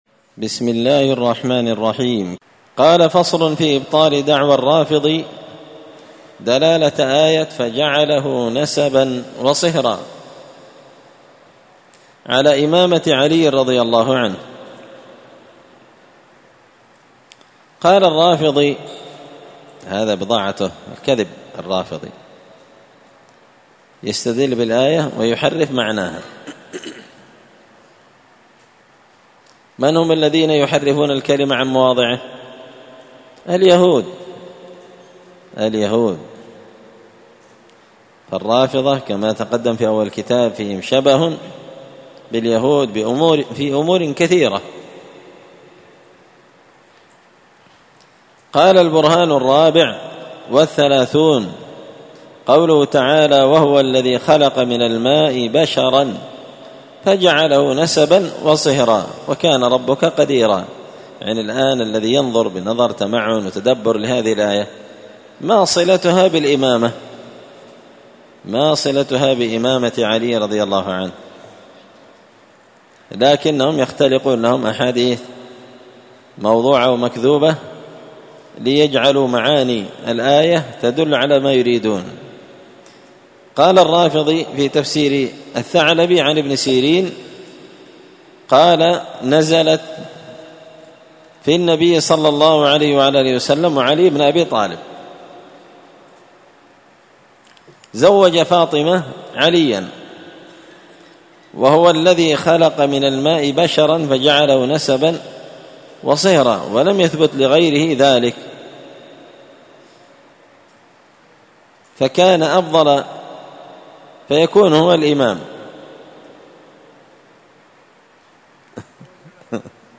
الأربعاء 14 صفر 1445 هــــ | الدروس، دروس الردود، مختصر منهاج السنة النبوية لشيخ الإسلام ابن تيمية | شارك بتعليقك | 80 المشاهدات
مسجد الفرقان قشن_المهرة_اليمن